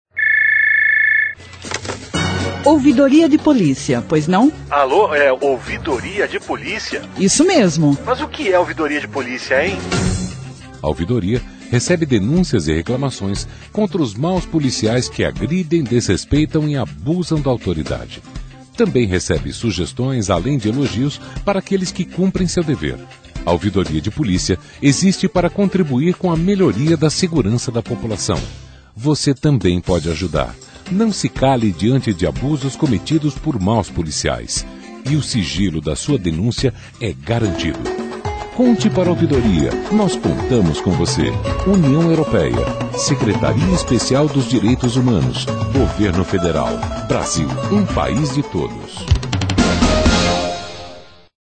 Faixa 2 - Spot O que é